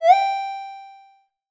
SFX_open.ogg